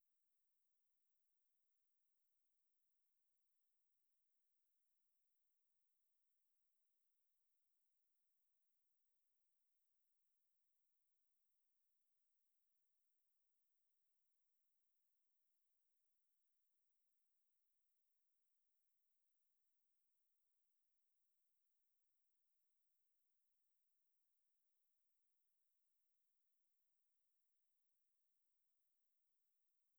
What I mean is send original signal on right channel and the same signal but shifted 180deg on the left channel.
Attached is an example with 500Hz tone, as you can see in the capture below the signals are opposite to each other on each channel. I use Audacity software to generate these signals.
pseudoDiff.wav